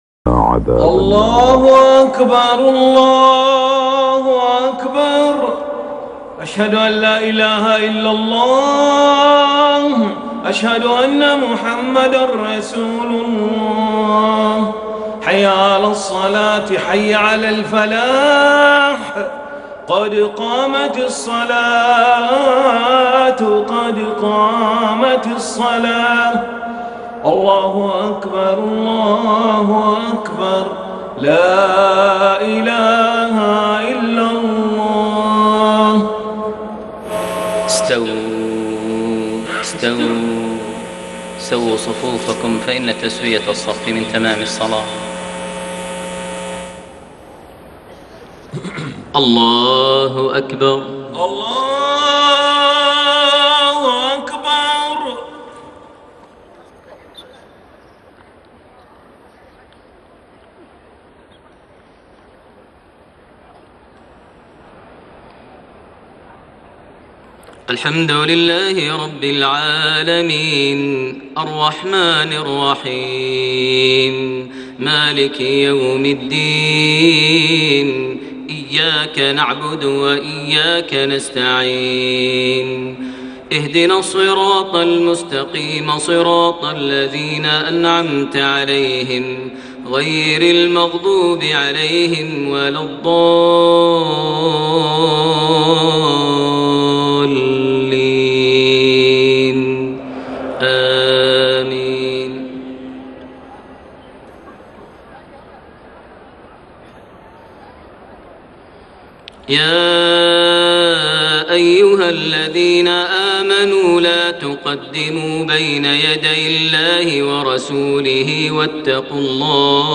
صلاة العشاء 6 شوال 1432هـ فواتح سورة الحجرات 1-11 > 1432 هـ > الفروض - تلاوات ماهر المعيقلي